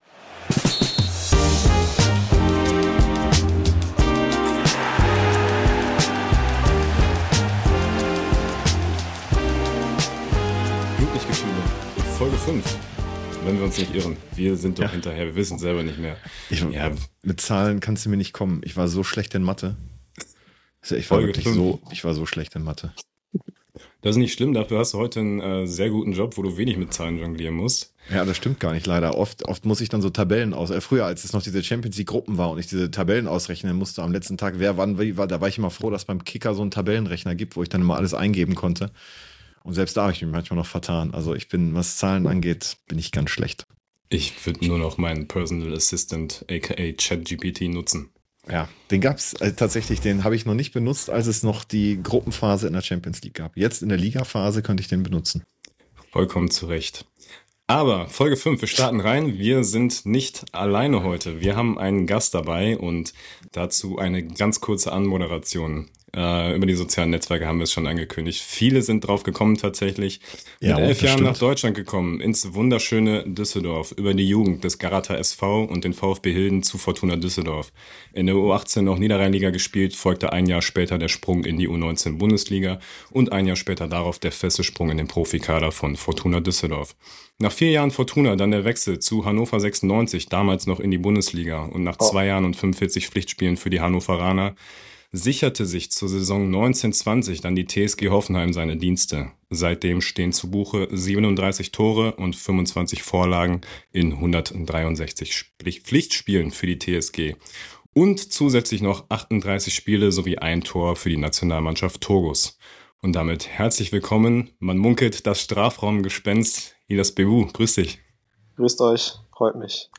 Ein ehrliches, ruhiges und trotzdem intensives Gespräch mit einem Bundesliga-Profi, der weiß, wie sich Verletzungen, Druck und Erwartungen anfühlen – und der trotzdem gelassen bleibt.